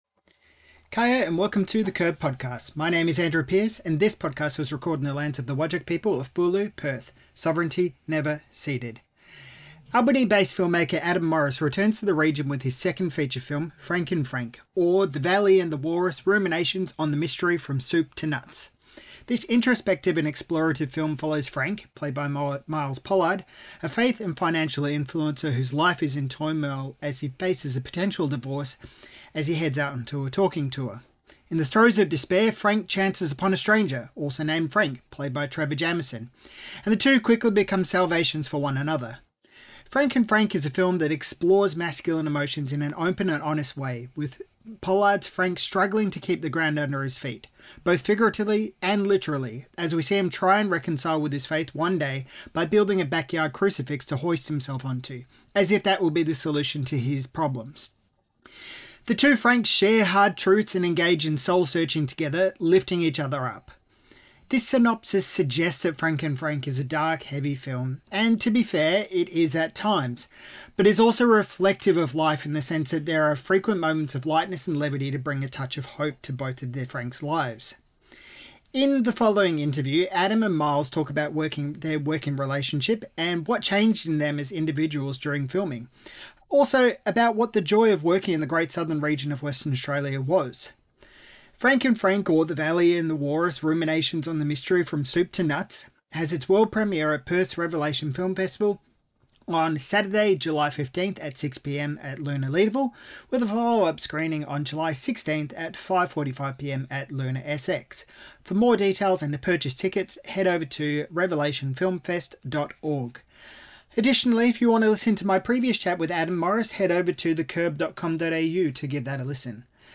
This interview contains discussion of mental health issues and suicide.